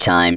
VEE Synth Voc 39.wav